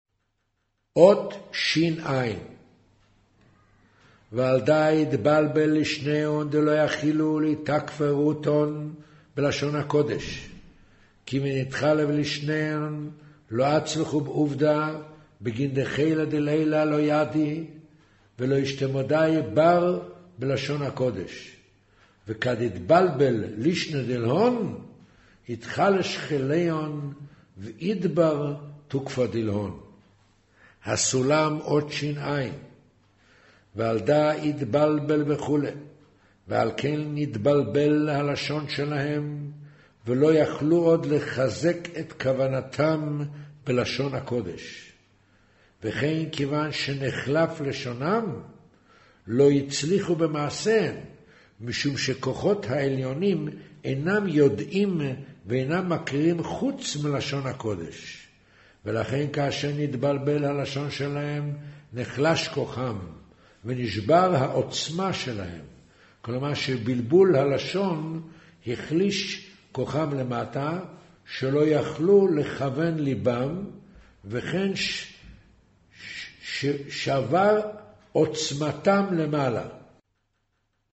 קריינות זהר